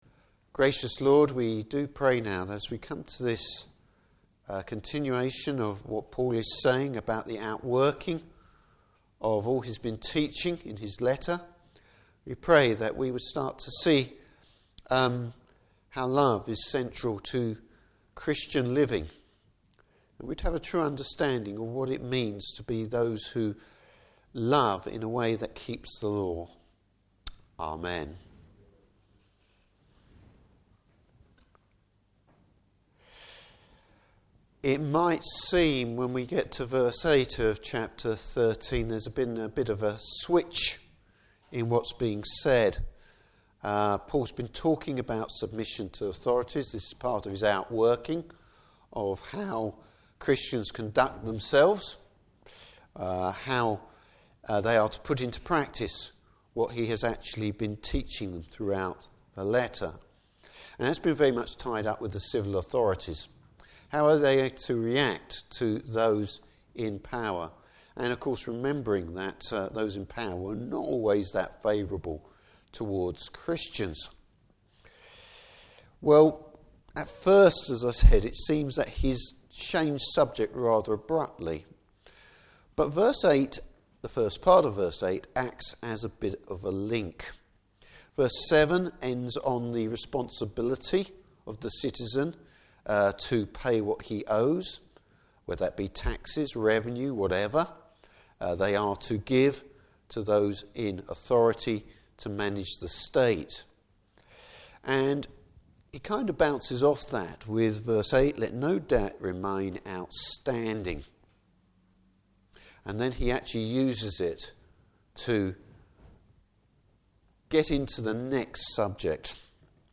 Service Type: Morning Service Love, the Christian’s motivation.